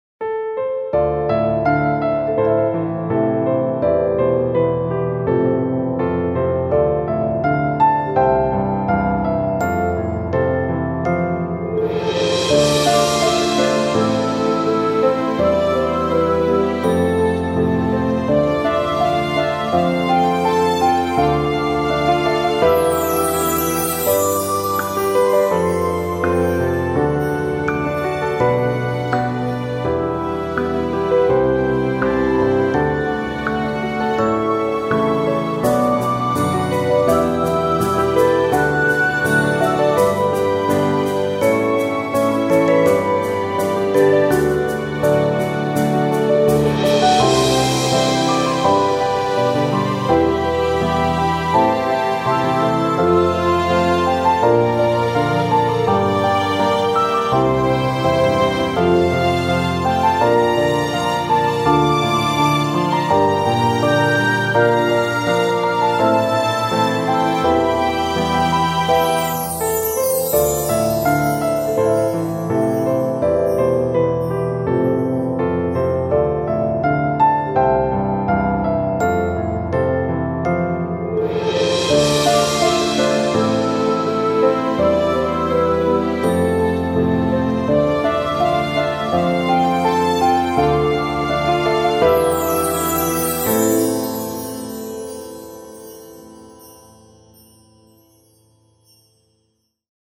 静かな雰囲気で、優しさも感じる、少し風情のあるBGMです。
ピアノ フルート
優雅 優しい 癒し 和風 落ち着く 幻想的